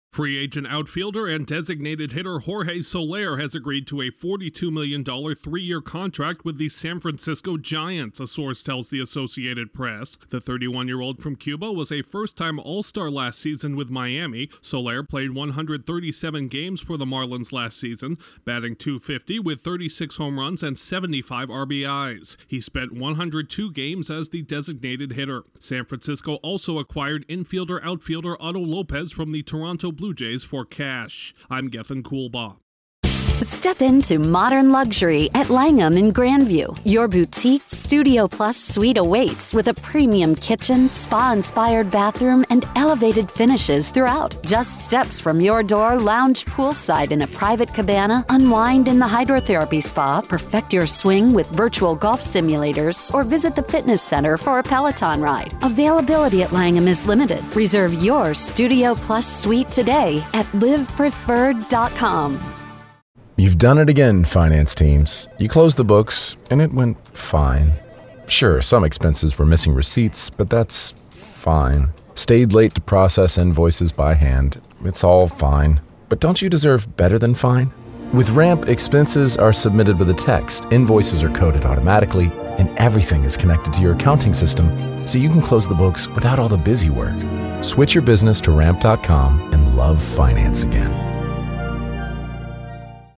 A National League West team has landed one of the top hitters remaining on baseball's free agent market. Correspondent